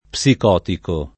psicotico [ p S ik 0 tiko ]